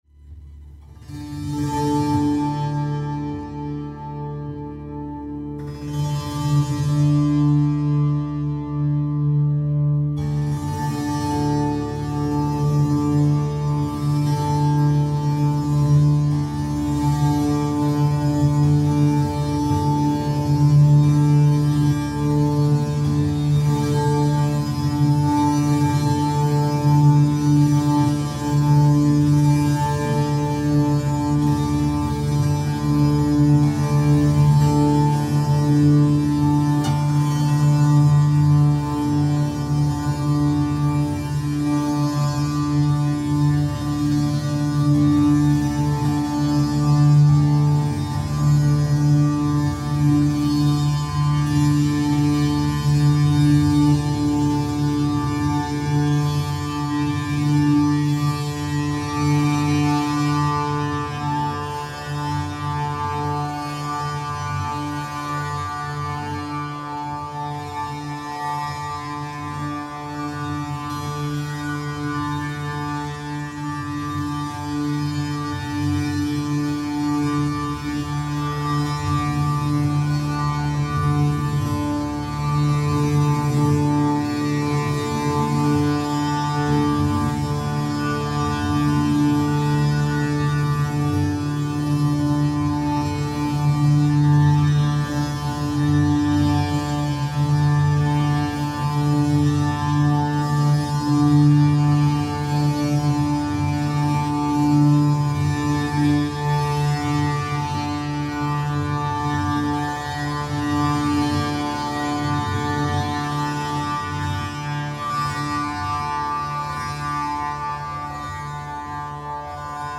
Wölbmonochord, 135 cm, 24-saitig,
Tamburastimmung A/d/d/D, ohne Steg
Die Klangbeispiele demonstrieren unterschiedliche Grundstimmungen und Klangfarben des Monochords als Klanginstrument mit und ohne Steg, die bei allen Modellen realisierbar sind.
Monochorde-Stereo.mp3